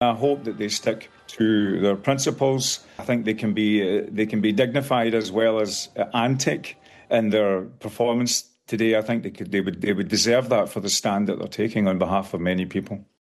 Today’s performance is expected to draw record crowds and musician Pat Kane doubts they’ll tone it down: